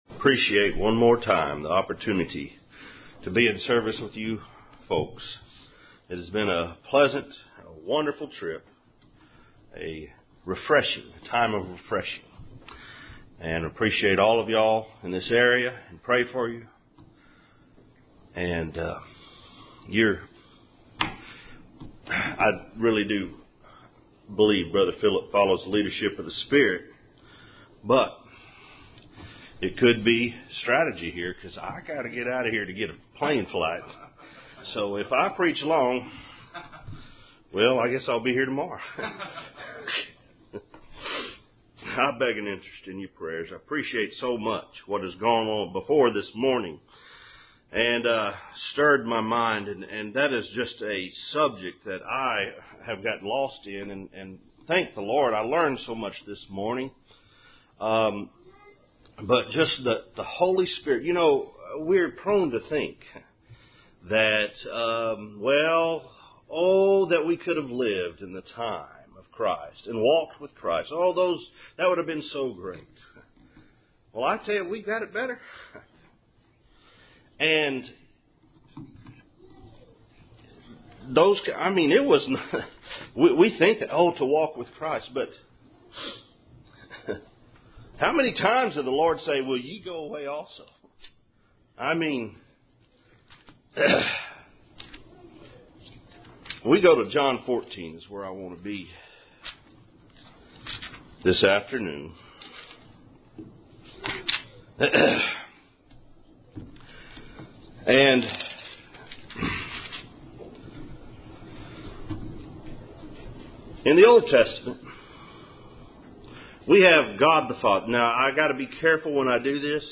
Service Type: Cool Springs PBC August Annual Meeting